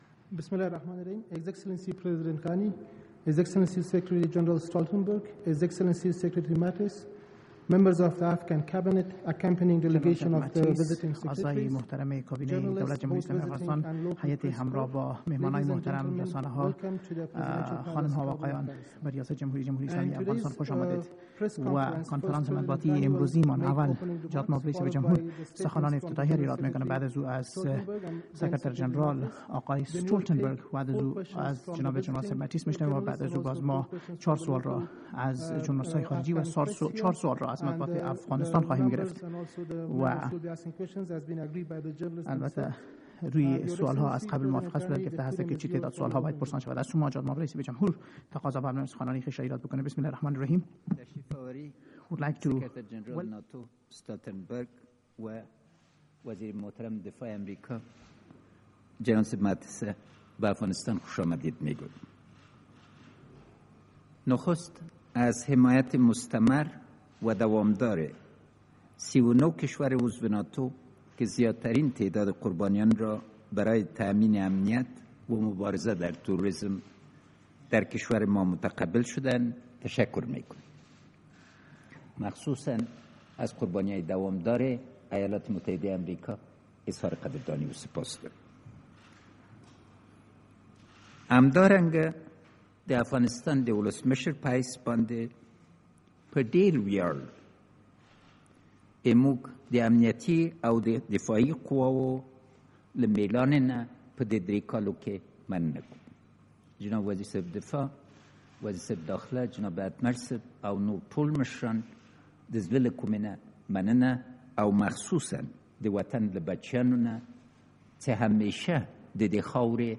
Joint press conference
with NATO Secretary General Jens Stoltenberg, President Ashraf Ghani of the Islamic Republic of Afghanistan and US Secretary of Defense James Mattis - Secretary General's opening remarks